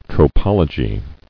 [tro·pol·o·gy]